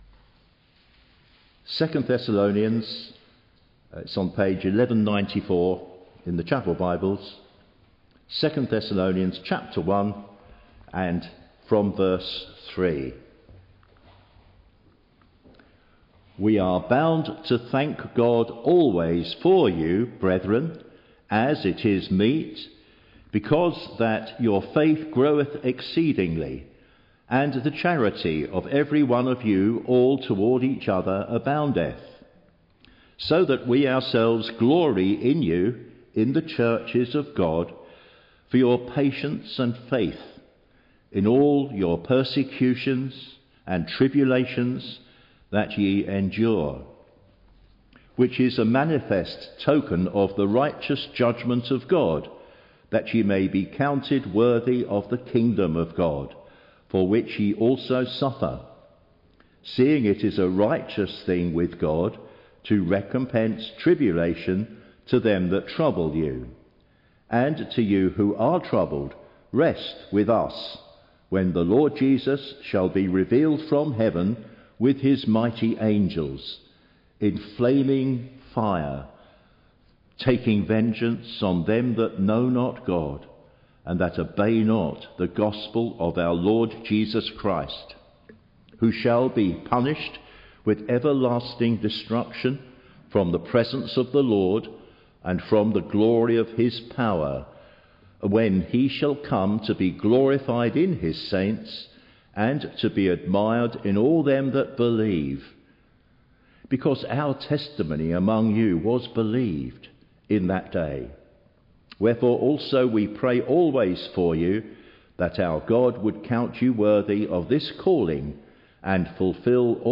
Click on the button below to listen to our Recent Sermons.